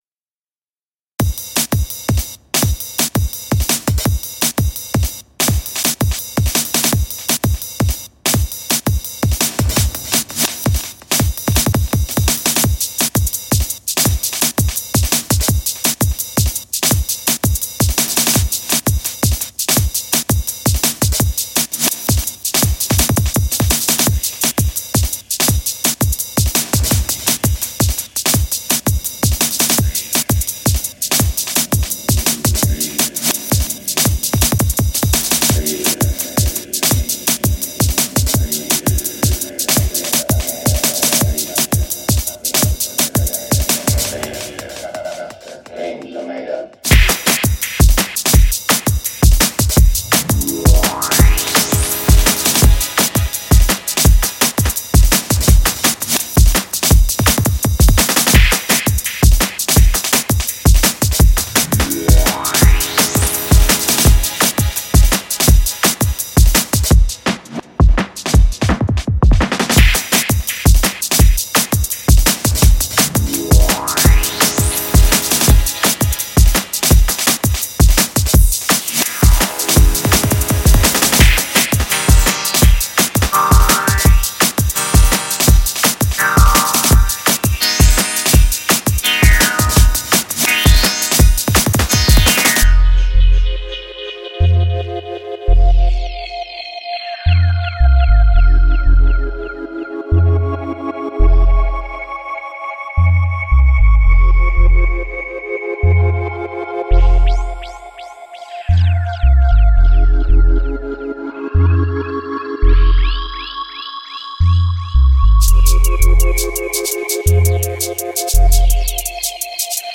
I took on the lessons from challenge 1 so tried to work on the mix and how many elements I had going on, plus improve the drum work with more variation and using (my own re-)sampled breaks rather than just straight drum machine.
The whole thing is 99% created on the op-z and 100% performed/recorded in one take using two patterns.
The pads use Cluster engine, and the wooshing sound and stab chords are the Digital engine.
The tape track is heavily used to add extra layers and depth to the pads and chords.
Zero DAW or post-recording processing was involved. Straight record from op-z to iPhone and then convert to a wav.